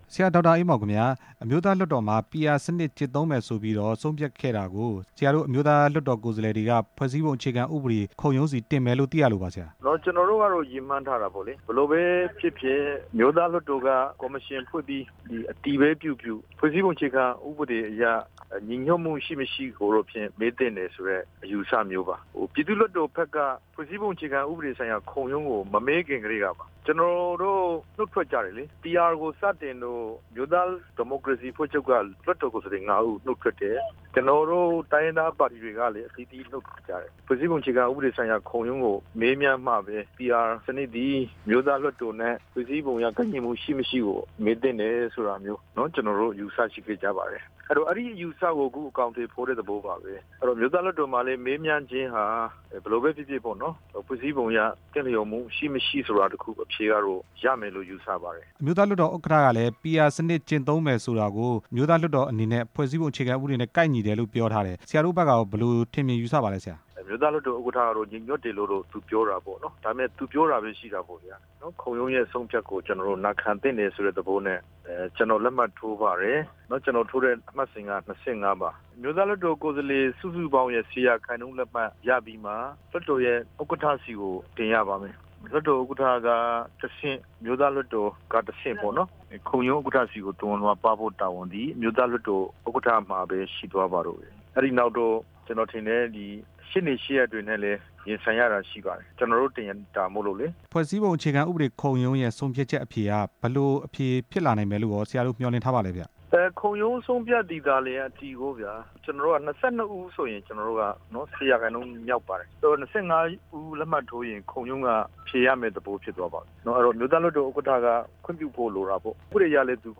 အမျိုးသား လွှတ်တော်ကိုယ်စားလှယ် ဒေါက်တာအေးမောင်နဲ့ မေးမြန်းချက် အပြည့်အစုံ